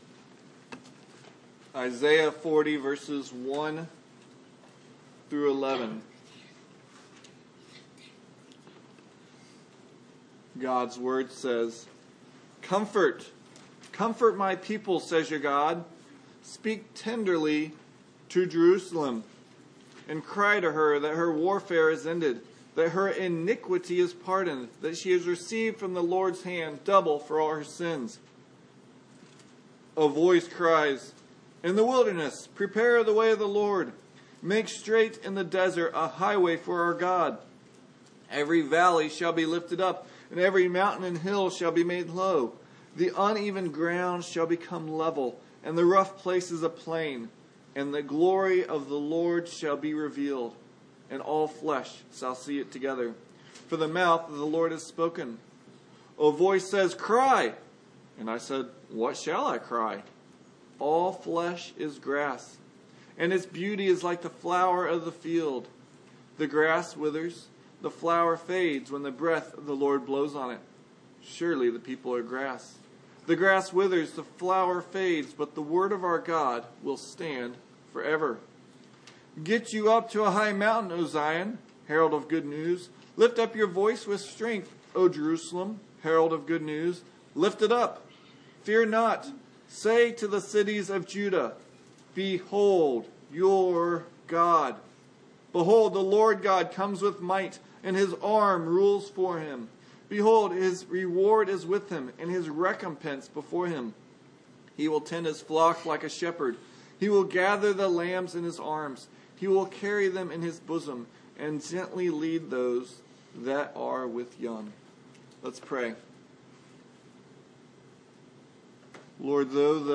Passage: Isaiah 40:1-11 Service Type: Sunday Morning